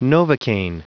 Prononciation du mot novocaine en anglais (fichier audio)
Prononciation du mot : novocaine